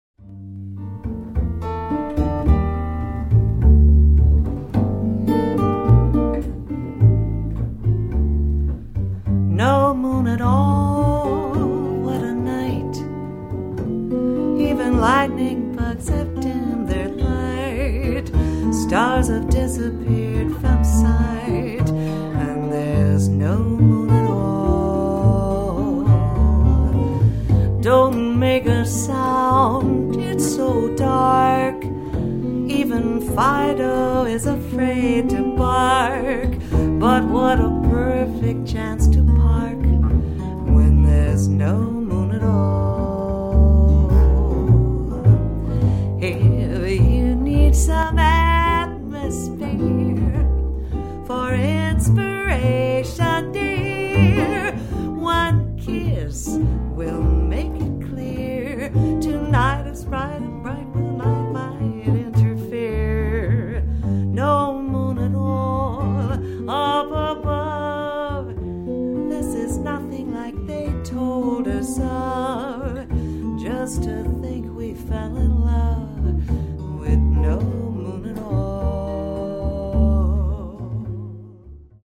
"This is a demo-quality disc.